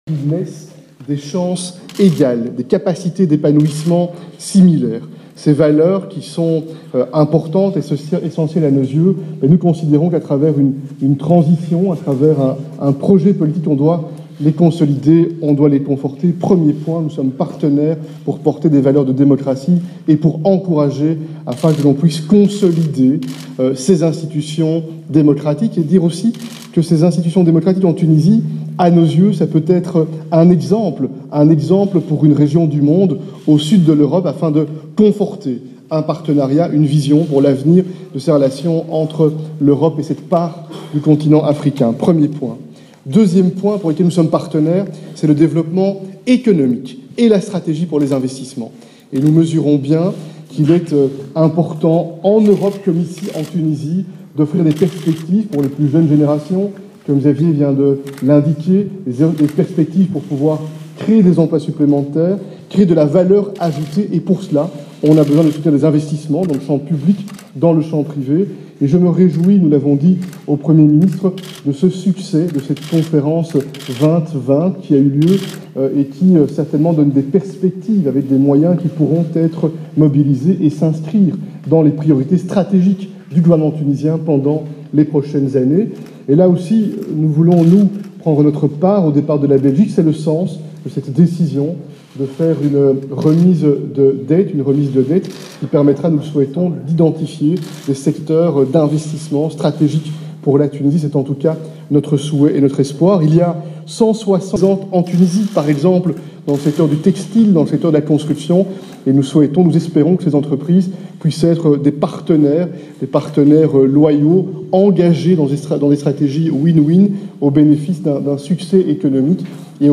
أعلن الوزير الاول البلجيكي شارل ميشال، ان بلاده ستحول جزءا من ديون تونس الى مشاريع إستثمارية من أجل تنشيط الاقتصاد التونسي، وذلك خلال ندوة صحفية انعقدت اليوم الاثنين بقصر الحكومة بالقصبة، في أعقاب لقاء جمع رئيس الحكومة يوسف الشاهد بالوزراء الأول لدول "البينيلوكس" (بلجيكيا وهولندا ولوكسمبورغ)، وهم على التوالي شارل ميشال ومارك روت وكزافيي بتال.